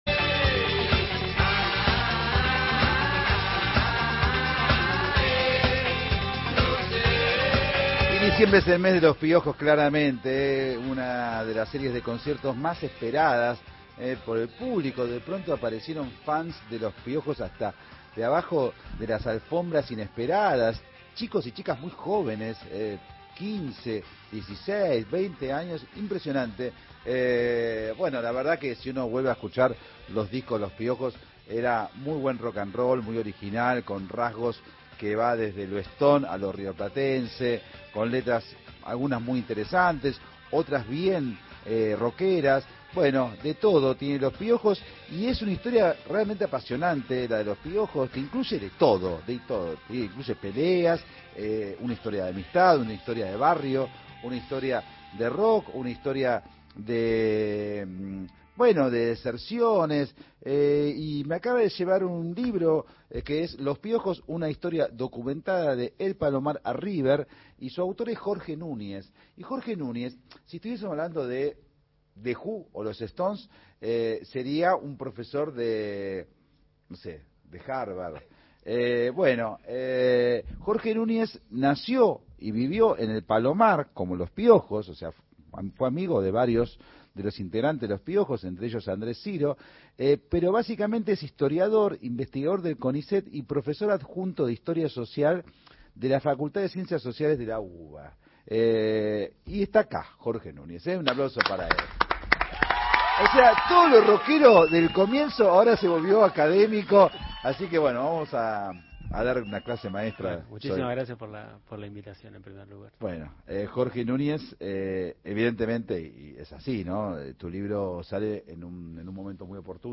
ENTREVISTA EN ESTUDIOS